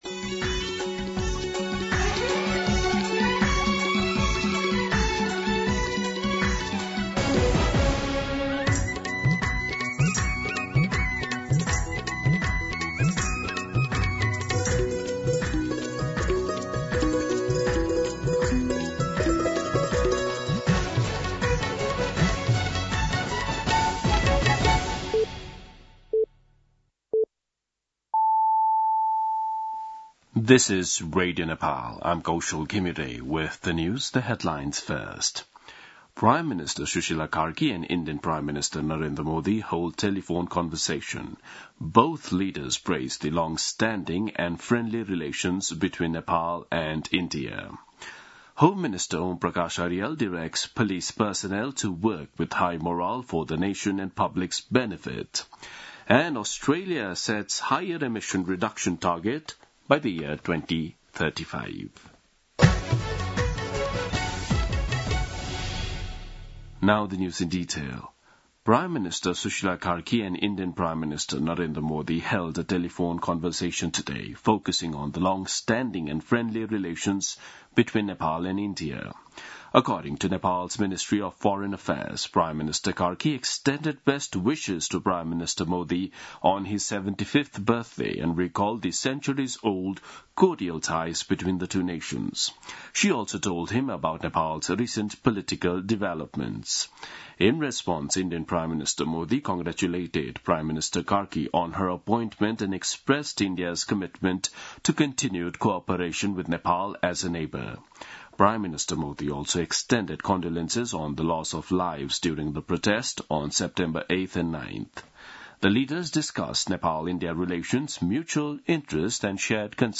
दिउँसो २ बजेको अङ्ग्रेजी समाचार : २ असोज , २०८२